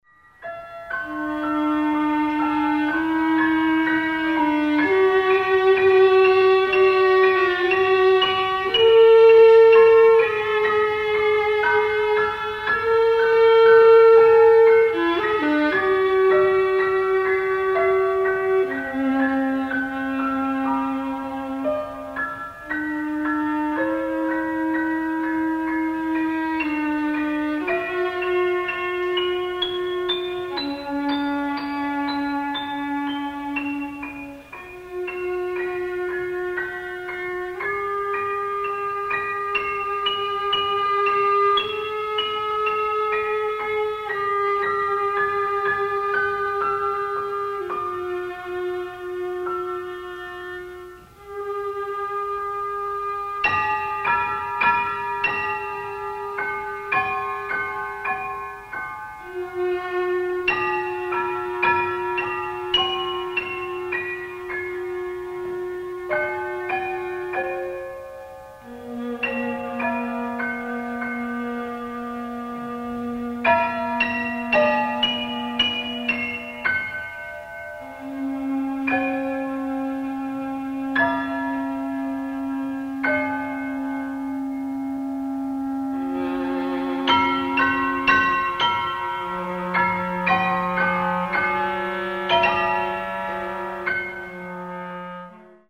Clarinet, Viola, Piano